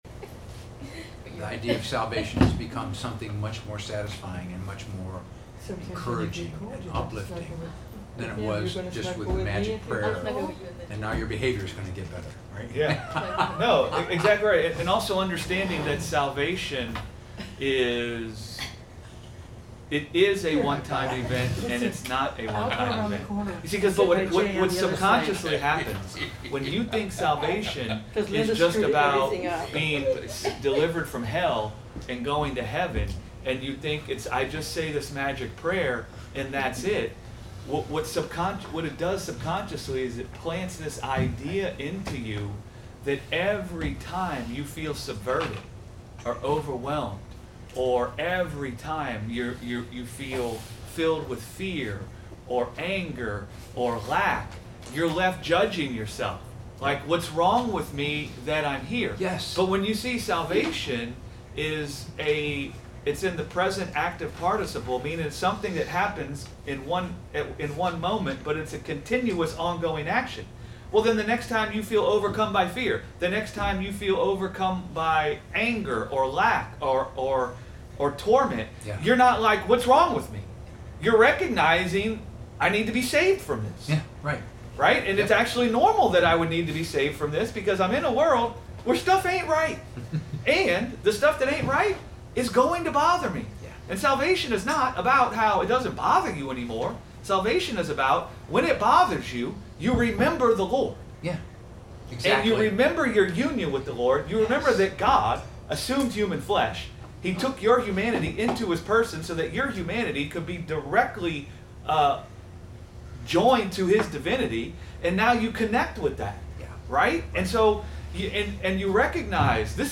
Topic Bible Study